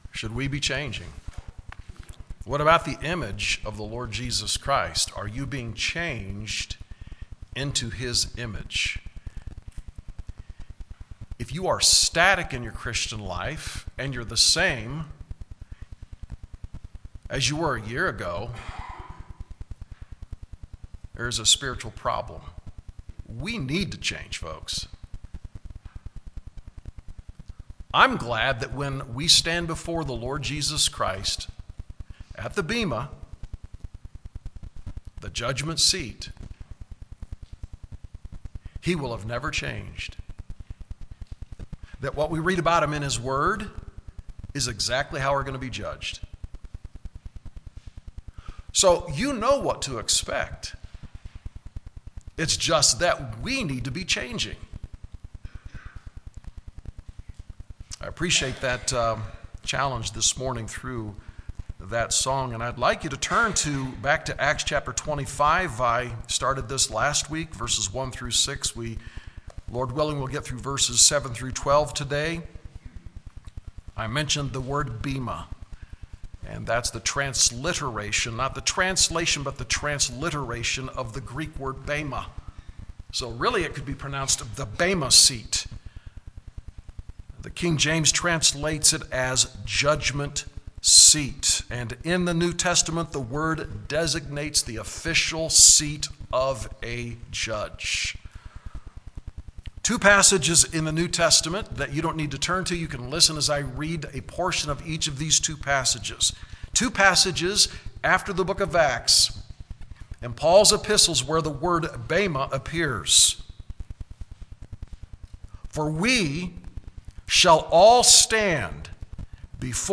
Sermons | Westside Baptist Church - Greeley, CO